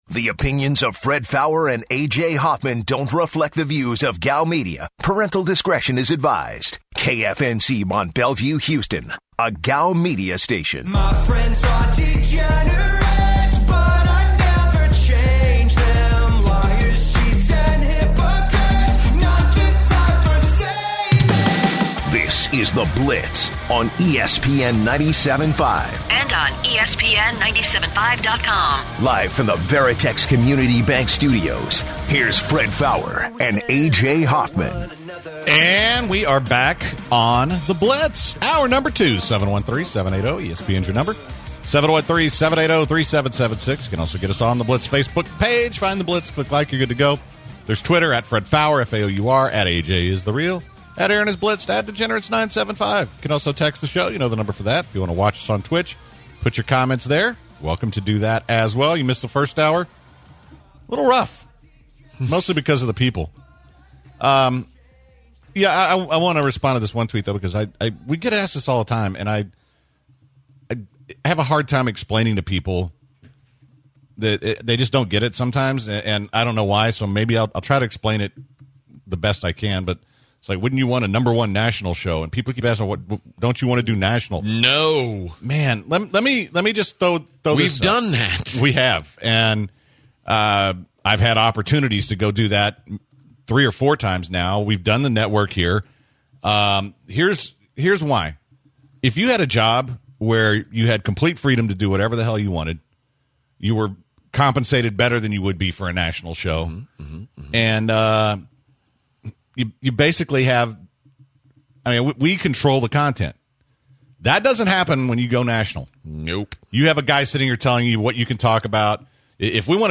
The guys also reluctantly field a couple calls that and also jump into Travis Etienne coming back for another season at Clempson. Lastly we have the gem of the day at the end of the hour where we find a constipation issue with a rare cure.